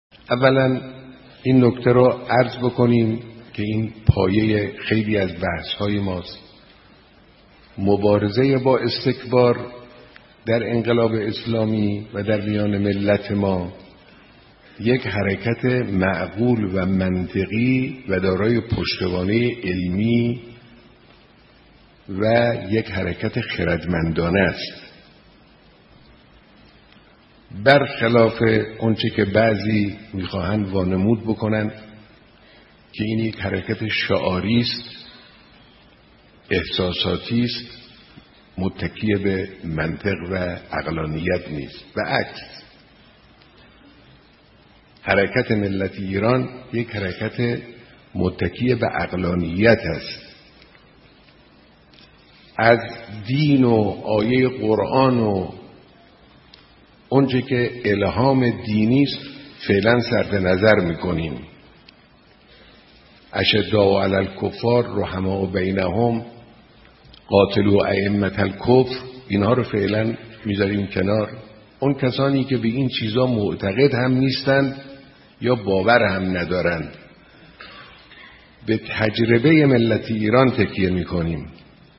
مقام معظم رهبری در سخنانی در خصوص استکبار ستیزی فرمودند: مبارزه با استکبار در انقلاب اسلامی و درمیان ملت ما یک حرکت معقول و منطقی و دارای پشتوانه علمی و یک حرکت خردمندانه است.